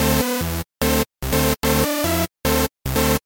硬式合成器150bpm 1 D
描述：一个硬风格的合成器线索
Tag: 150 bpm Hardstyle Loops Synth Loops 562.75 KB wav Key : D